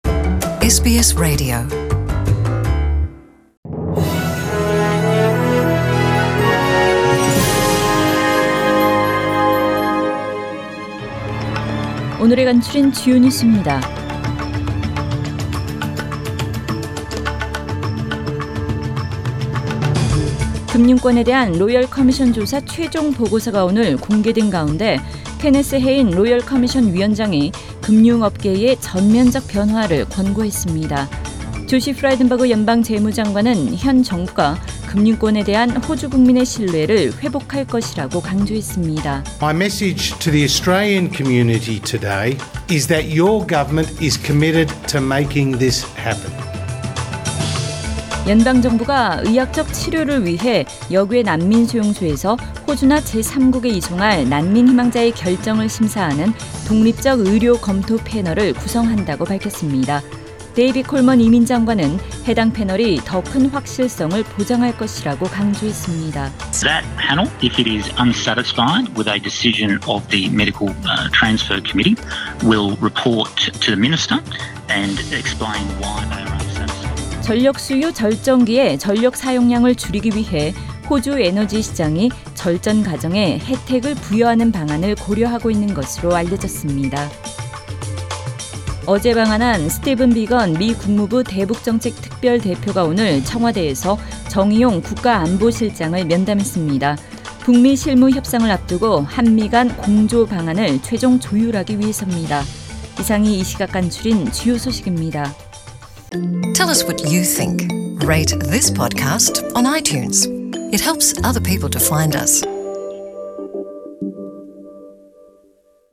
SBS 한국어 뉴스 간추린 주요 소식 – 2월 4일 월요일